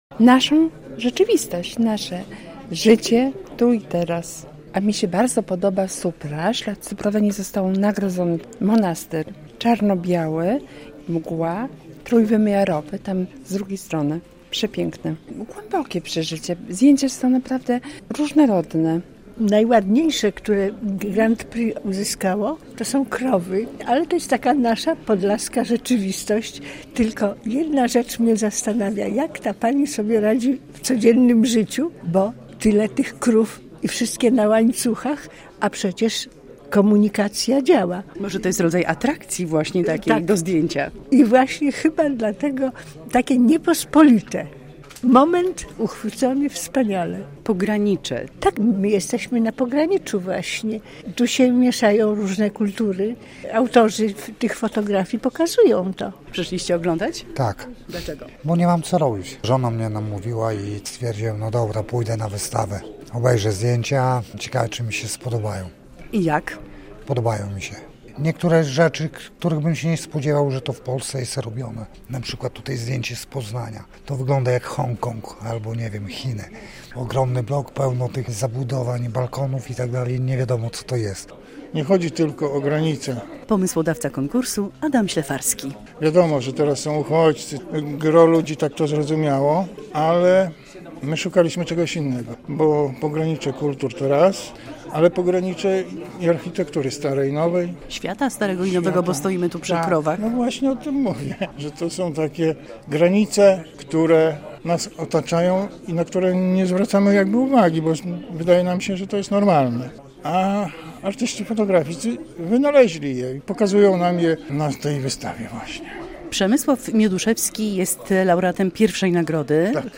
Pokonkursowa wystawa Pogranicze w PIK - relacja